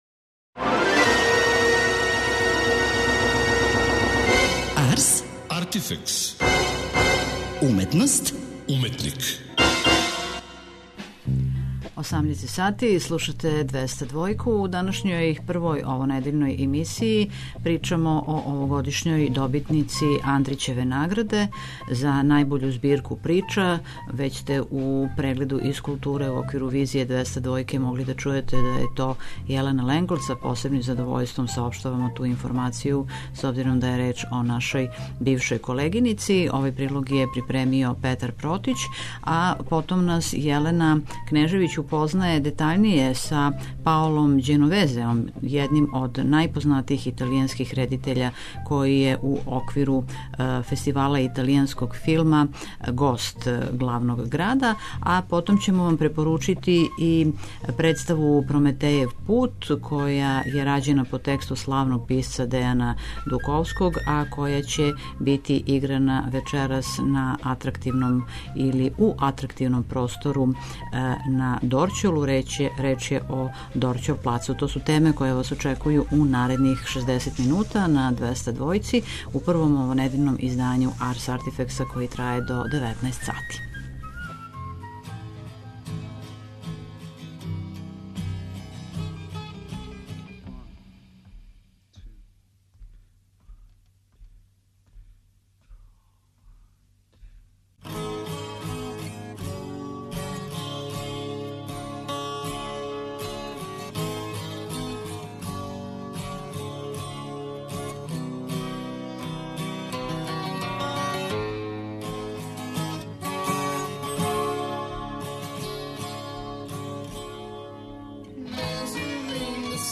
Ђеновезе у интервјуу тумачи планетарни успех овог свог остварења, које говори о тајним животима које живимо у ери мобилних телефона, говори о могућности да у Београду буде постављен позоришни комад по овом филму, о италијанској кинематографији и филму као медију данас.